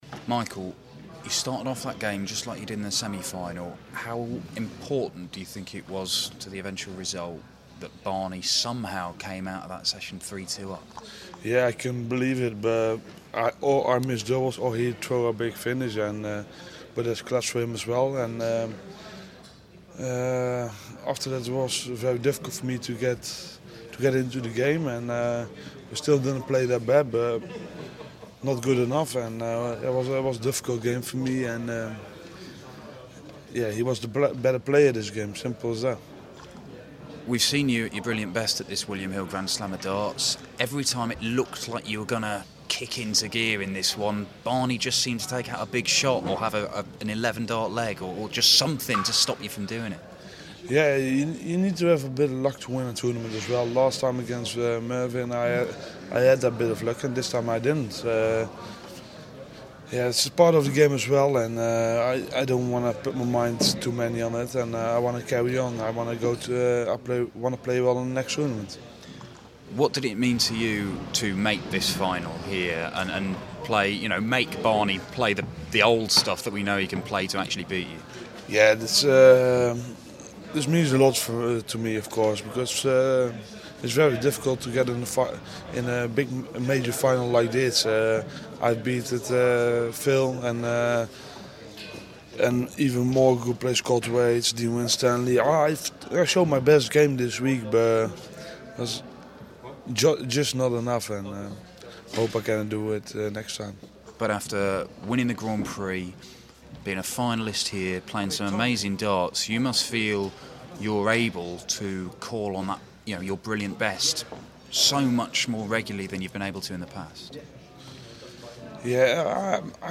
William Hill GSOD - van Gerwen Interview (Final)